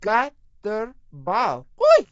gutterball-3/Gutterball 3/Commentators/Maria/maria_gut-ter-ball.wav at 0b195a0fc1bc0b06a64cabb10472d4088a39178a
maria_gut-ter-ball.wav